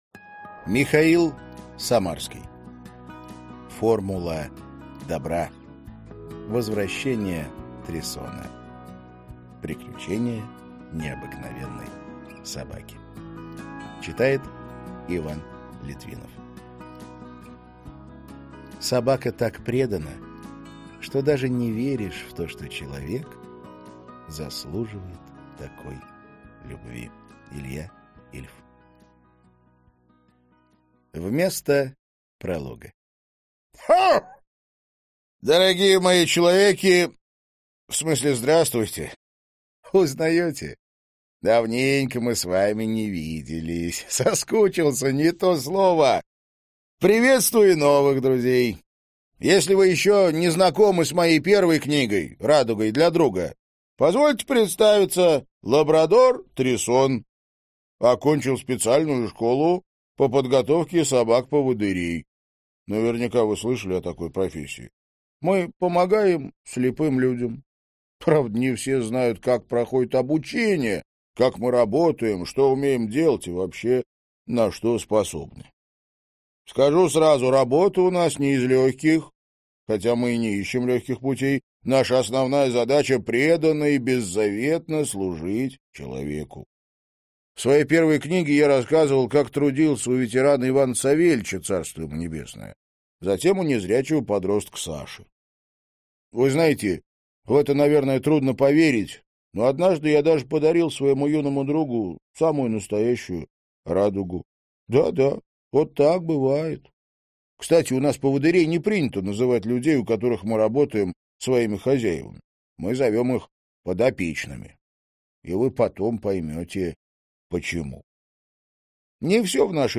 Аудиокнига Формула добра | Библиотека аудиокниг